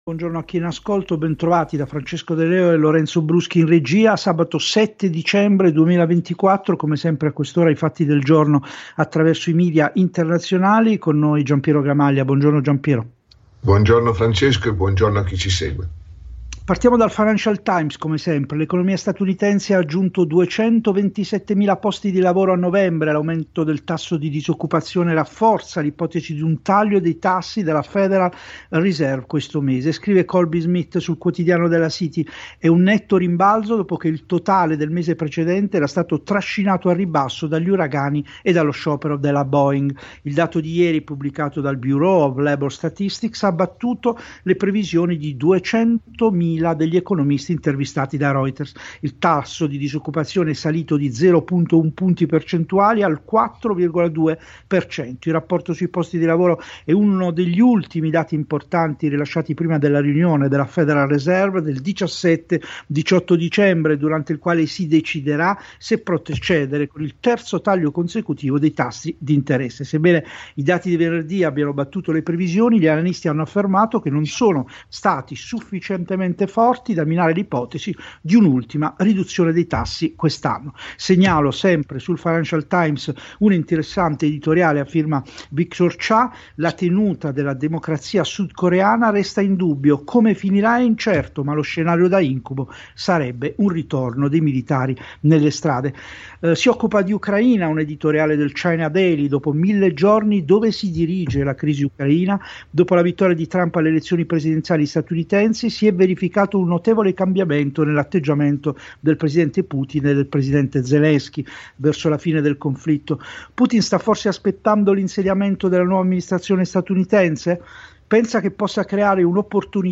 vai all’intervista radio (AUDIO).